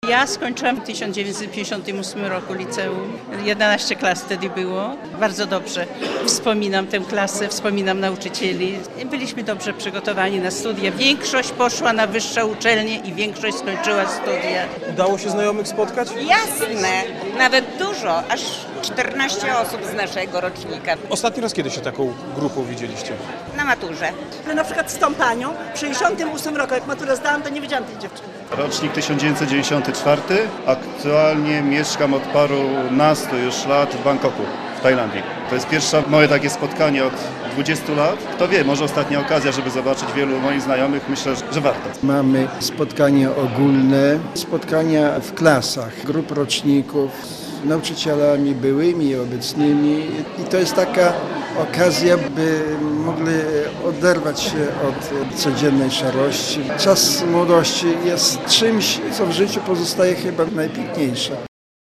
Zjazd absolwentów hajnowskiego liceum - relacja